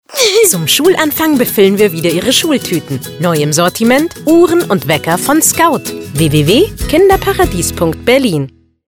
Unsere Werbespots auf 98,2 Radio Paradiso